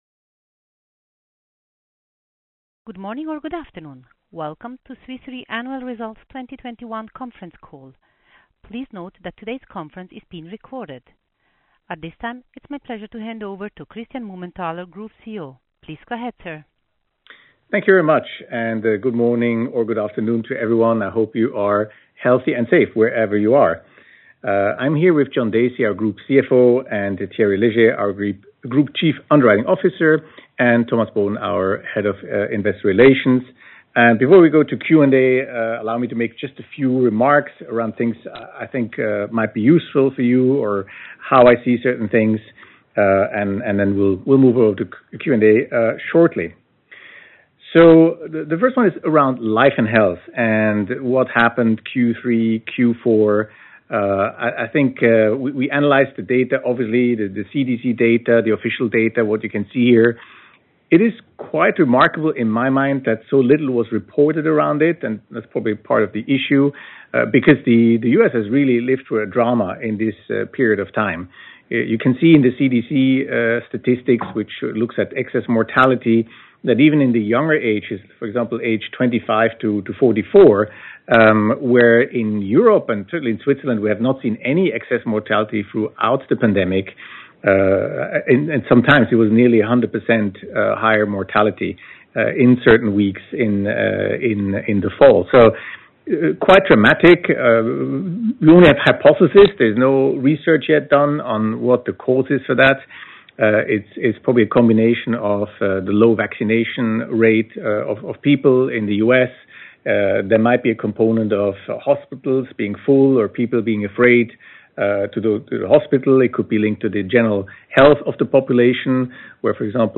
fy-2021-call-recording.mp3